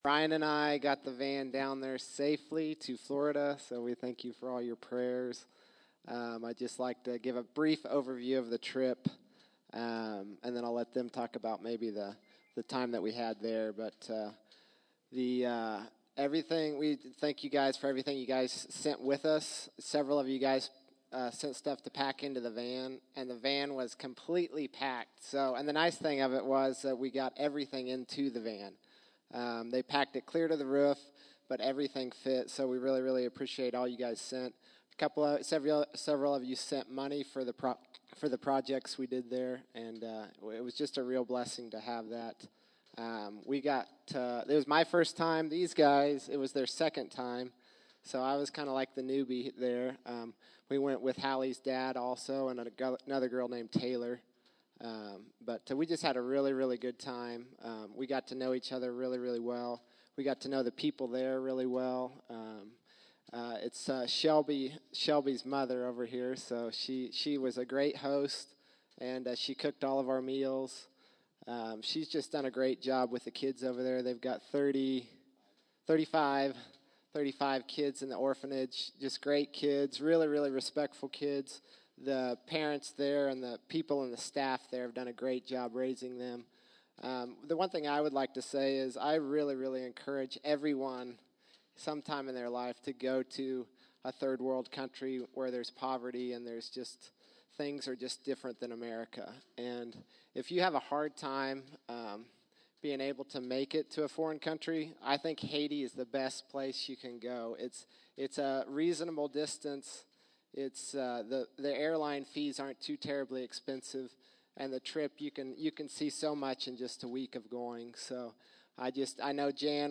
The Haiti team gives a report on their recent mission trip.